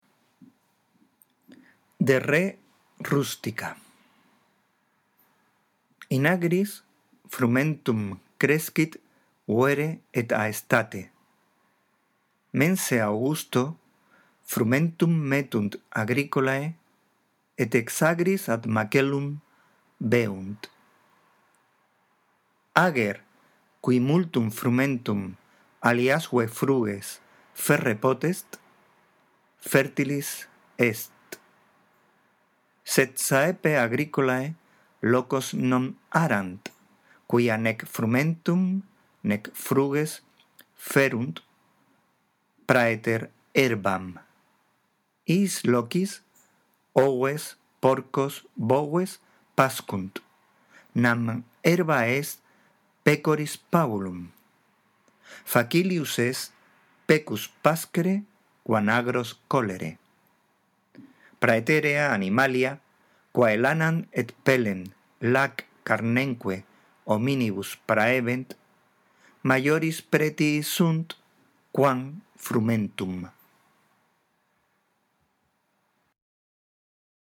La audición de este archivo te ayudará en la práctica de la lectura del latín